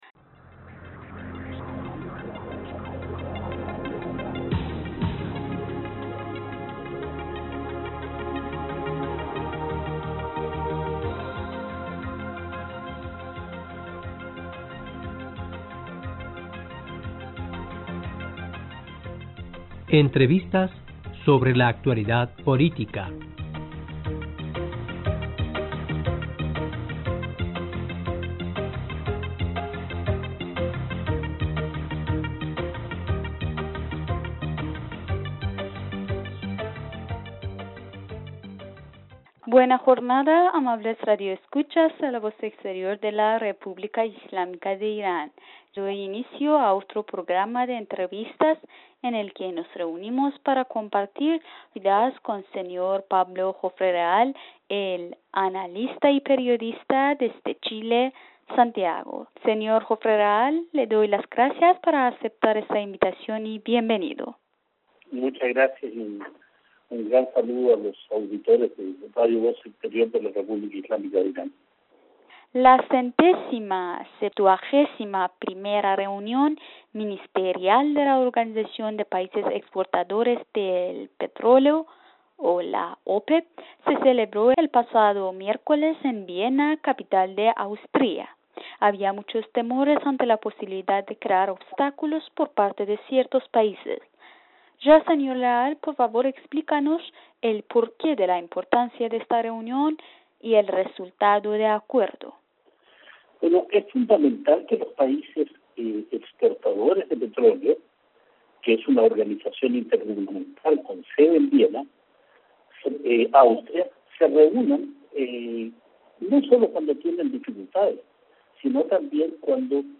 E: Buena jornada amables radio escuchas de la Voz exterior de RII, doy inicio a otro programa de entrevistas en el que nos reunimos para compartir con el S...